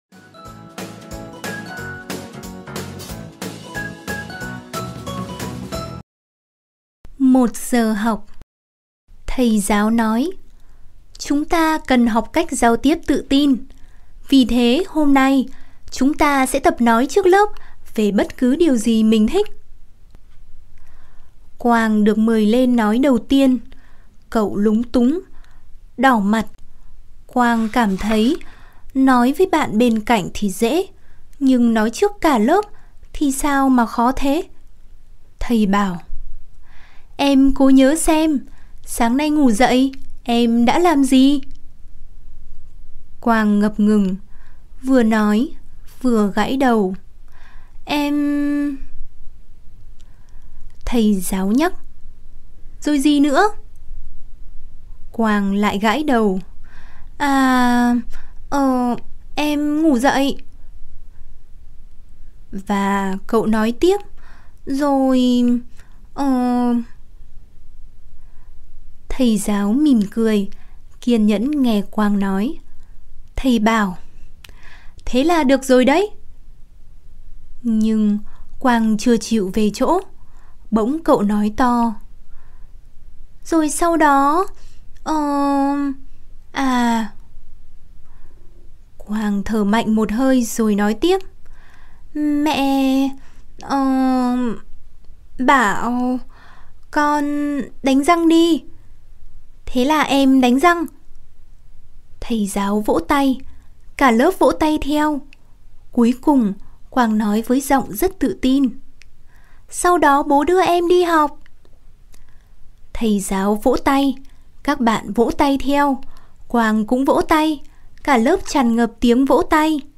Sách nói | Một giờ học